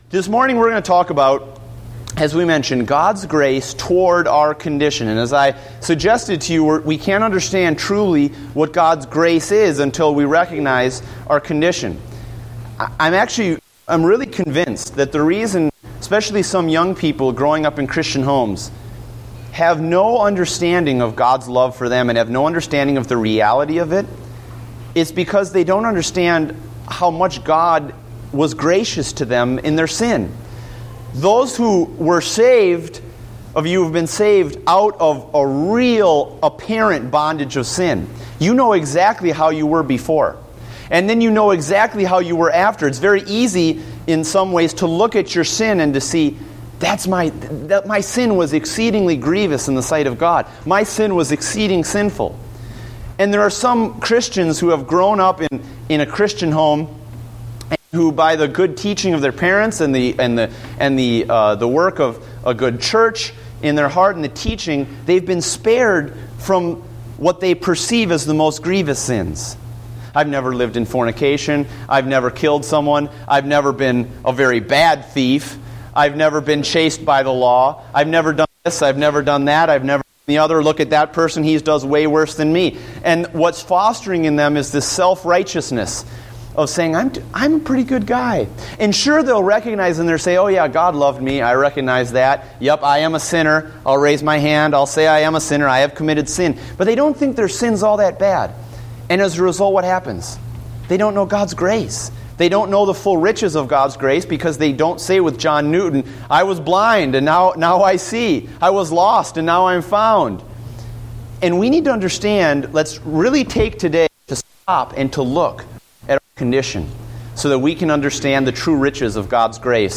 Date: June 1, 2014 (Adult Sunday School)